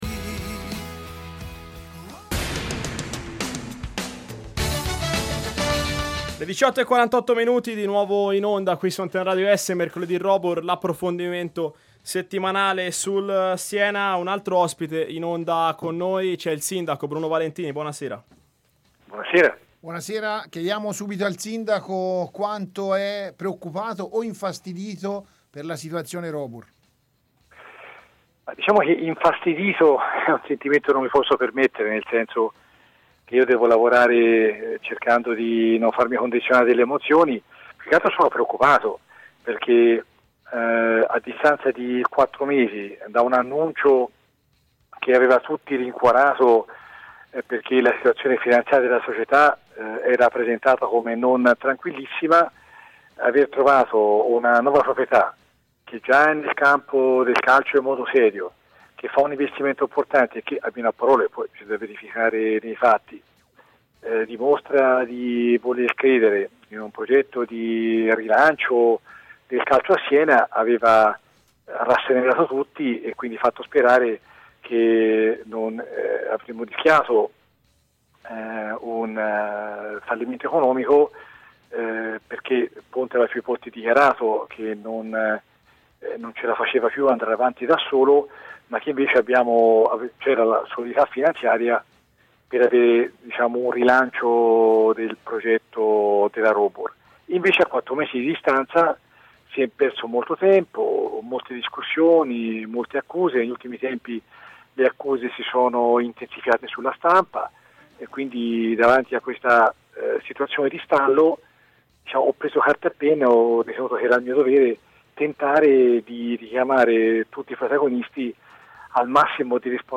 Riascolta l’intervento di Bruno Valentini